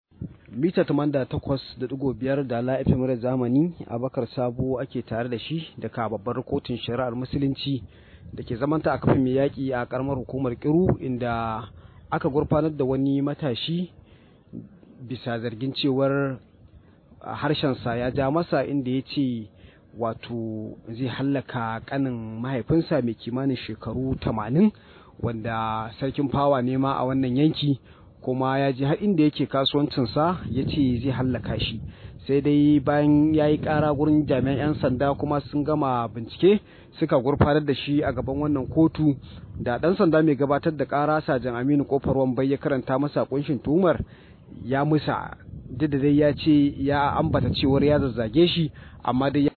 Rahoto: Ana zargin matashi da barazanar kashe kanin mahaifin sa